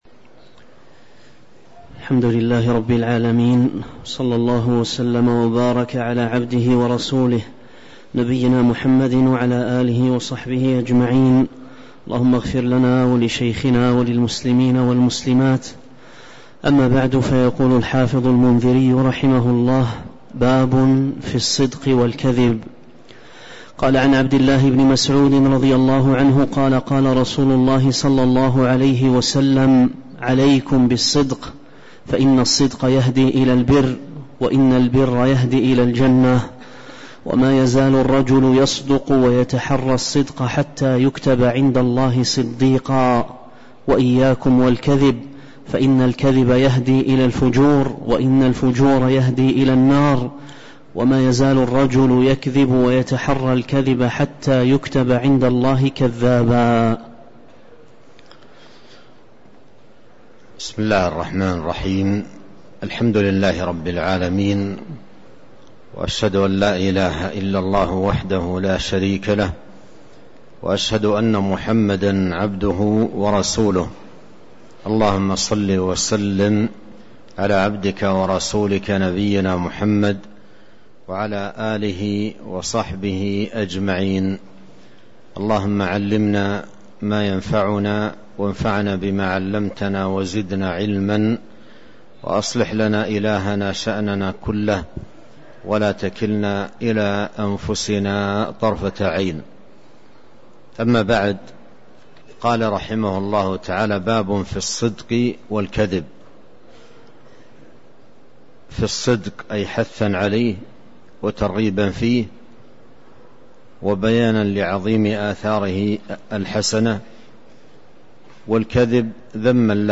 تاريخ النشر ١٨ ذو القعدة ١٤٤٣ هـ المكان: المسجد النبوي الشيخ